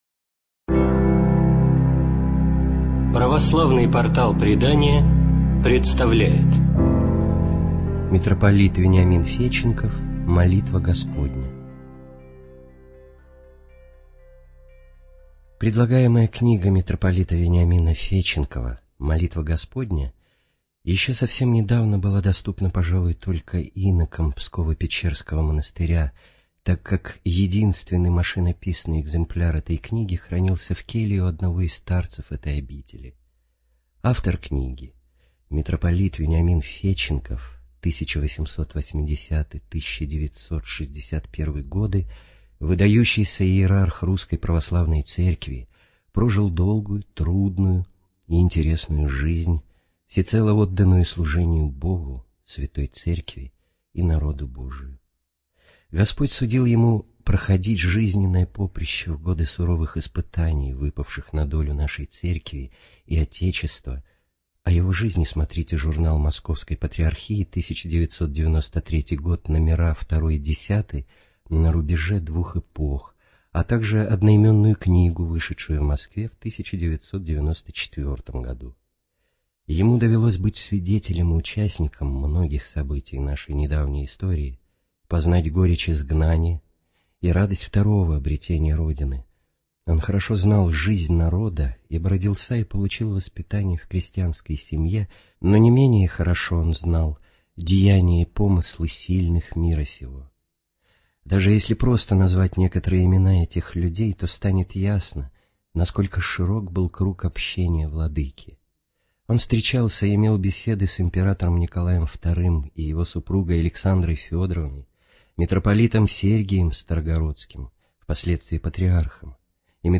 ЖанрХристианство